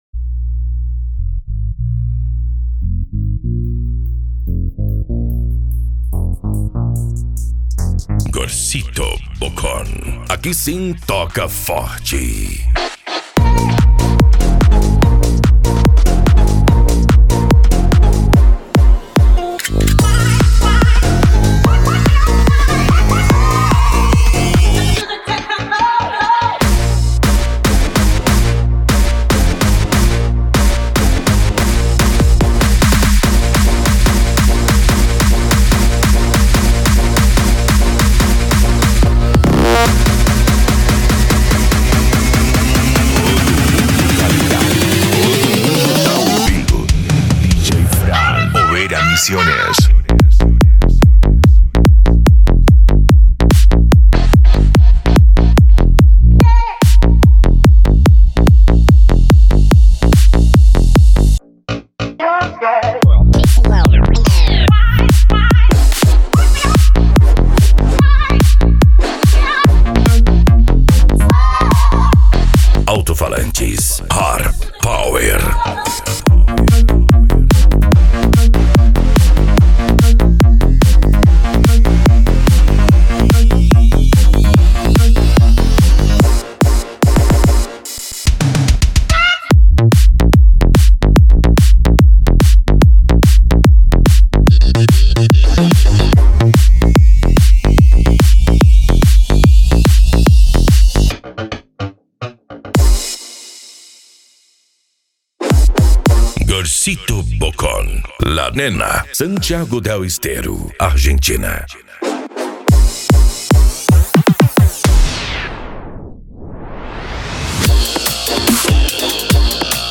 Remix
Bass